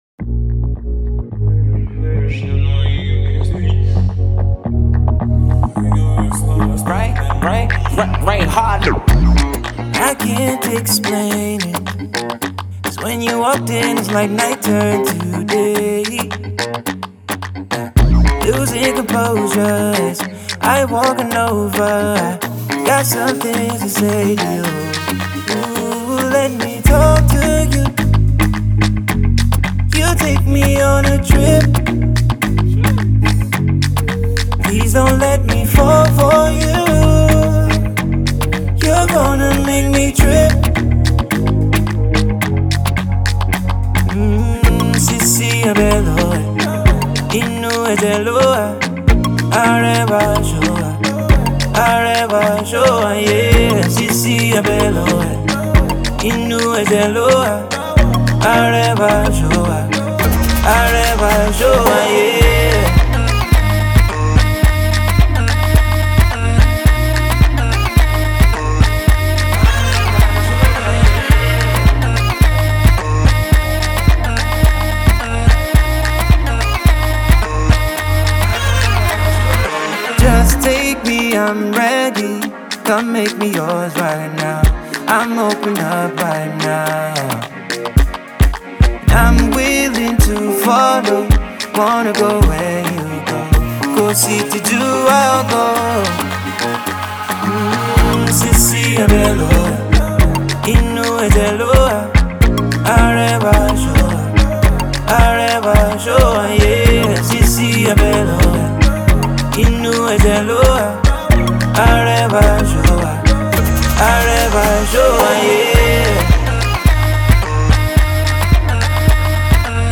silky vocals